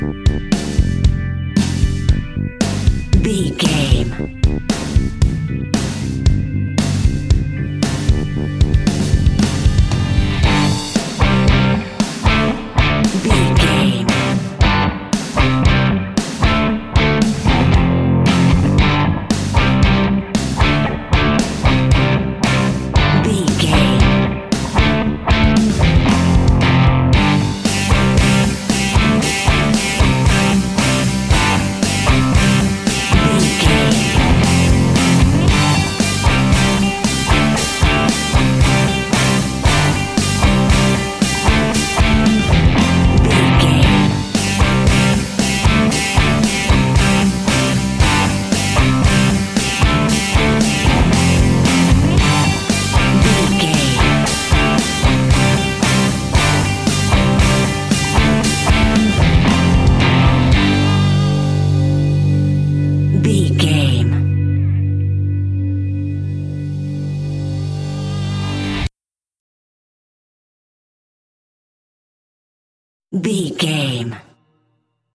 Epic / Action
Ionian/Major
hard rock
blues rock
rock guitars
Rock Bass
heavy drums
distorted guitars
hammond organ